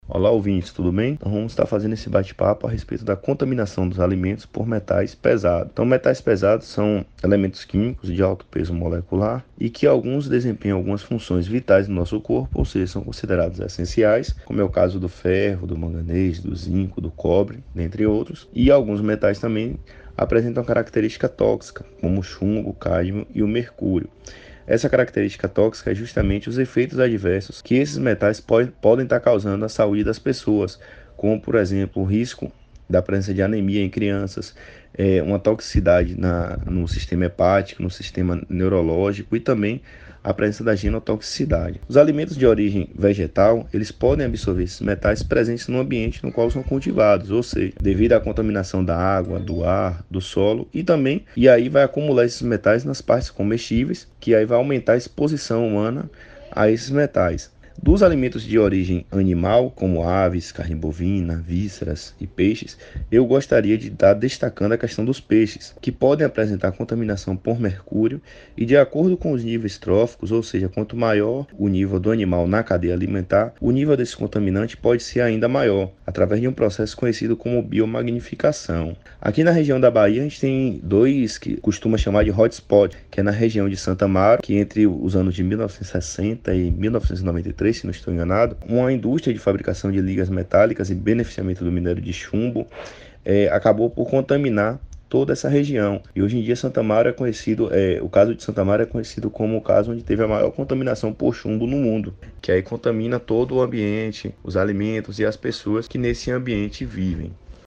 O asssunto foi tema do quadro Meio ambinte e saude veiculado no Progrma Saude no Ar, pela Rádio Excelsior da Bahia, AM 840 às quartas -feiras.